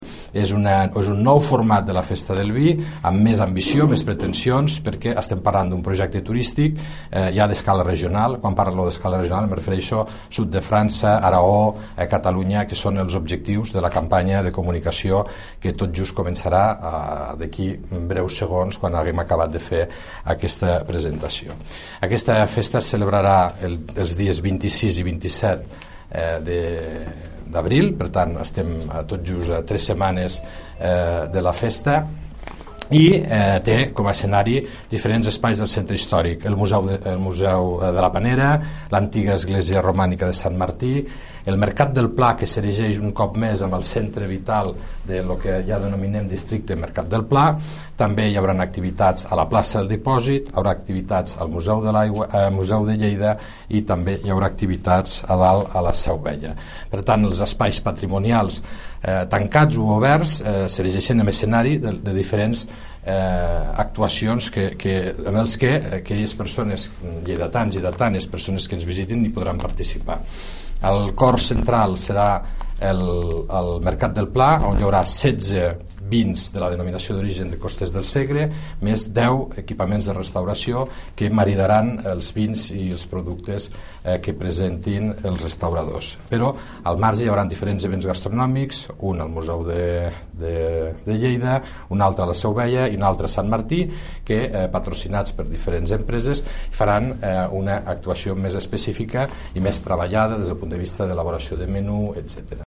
tall-de-veu-del-regidor-de-turisme-felix-larrosa-sobre-la-lleida-skrt-fest